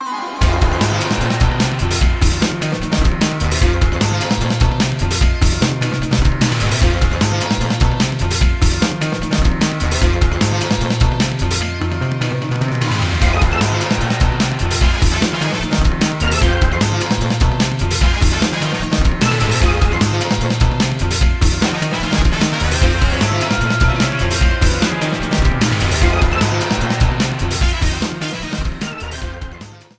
Trimmed and added fadeout